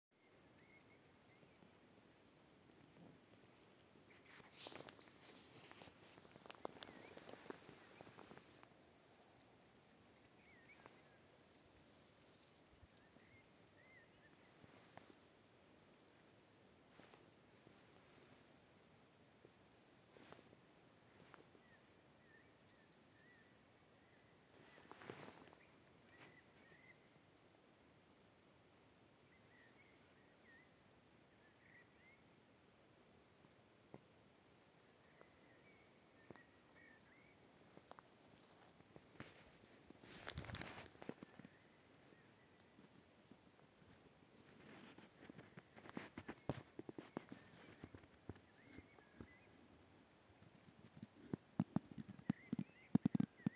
Blackbird, Turdus merula
Count2
StatusVoice, calls heard